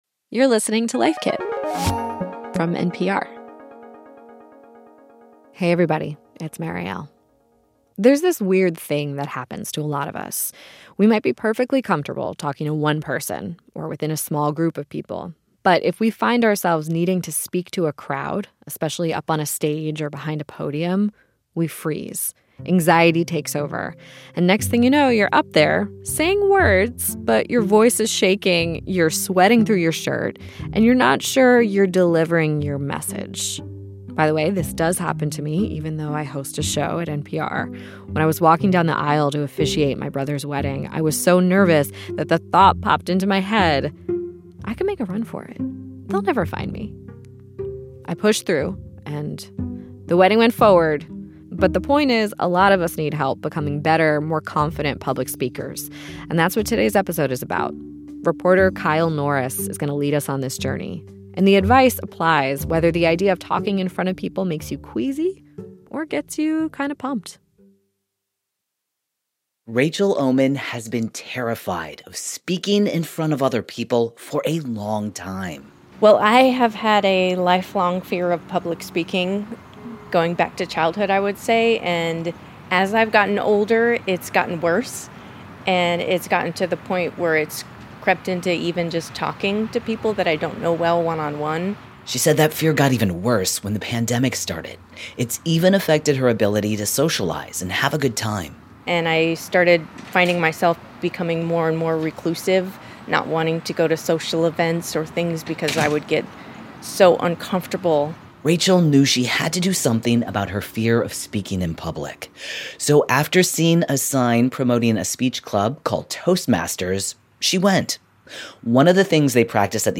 A speechwriter and a speech coach share their best tips.